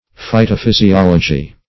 Search Result for " phytophysiology" : The Collaborative International Dictionary of English v.0.48: Phytophysiology \Phy`to*phys`i*ol"o*gy\, n. [Phyto- + physiology.]